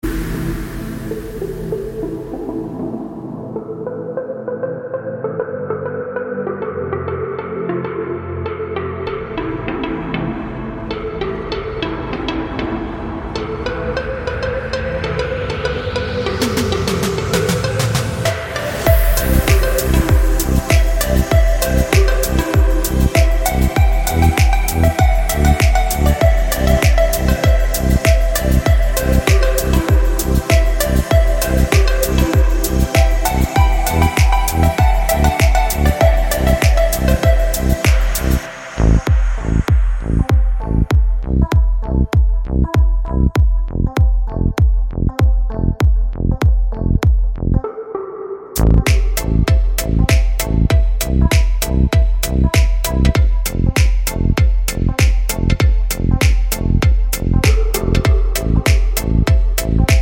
no Backing Vocals Finnish 3:32 Buy £1.50